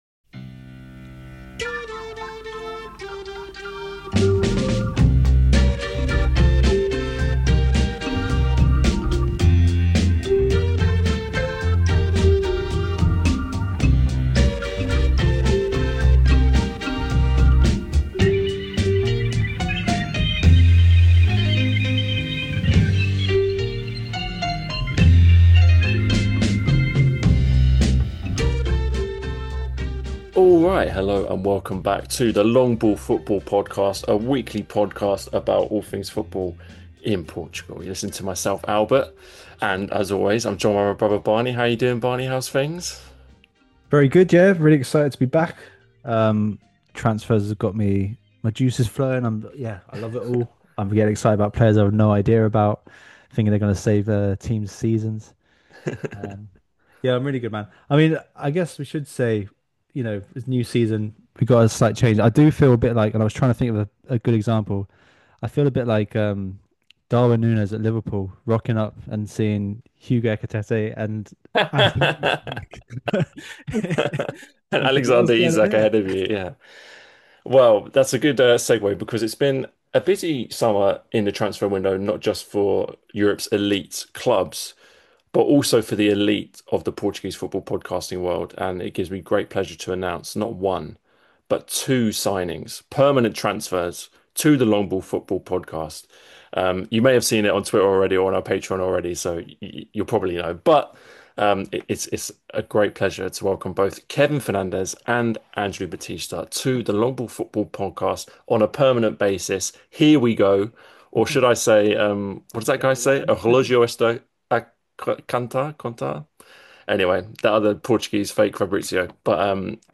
A weekly podcast by two brothers about all things football in Portugal 🇵🇹⚽🇬🇧 Join us each week for Primeira Liga chat, and discussion about Portuguese clubs' exploits in Europe!